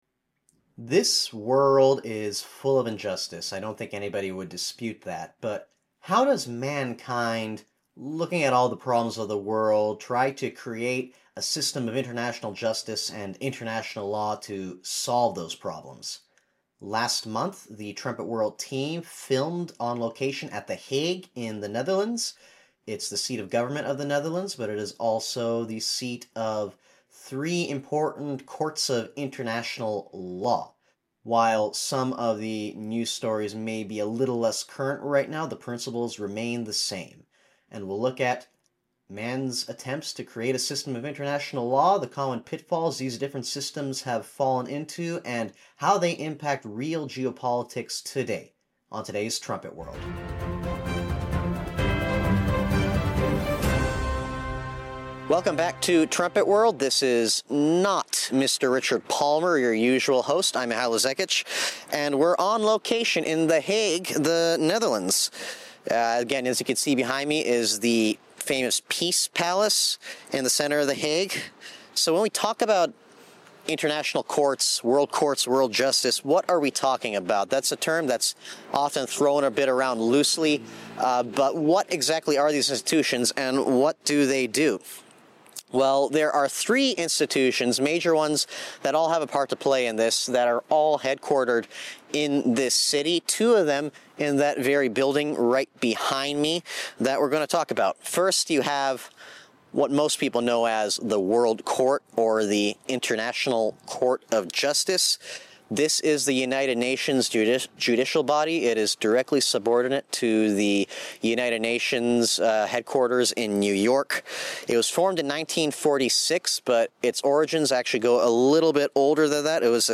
trumpet-world-128-an-unjust-world-special-report-from-the-hague.mp3